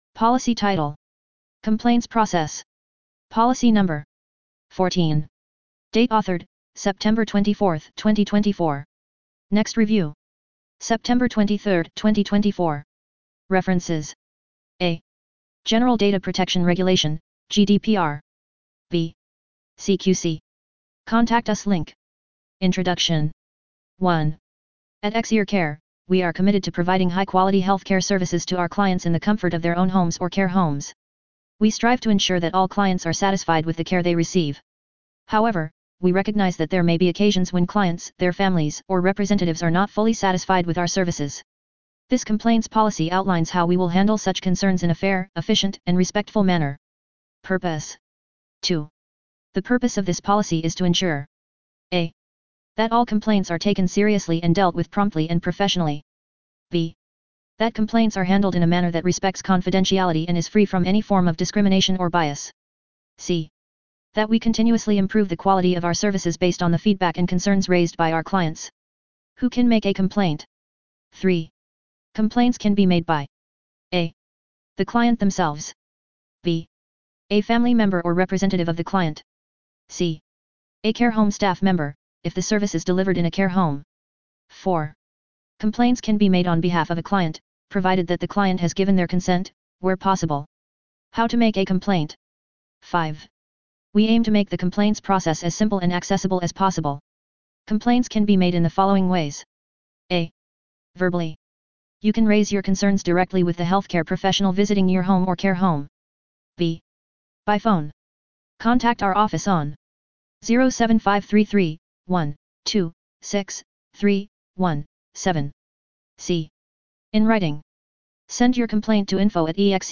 Narration of Complaints Policy